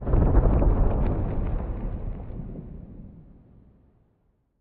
Minecraft Version Minecraft Version latest Latest Release | Latest Snapshot latest / assets / minecraft / sounds / ambient / nether / nether_wastes / mood5.ogg Compare With Compare With Latest Release | Latest Snapshot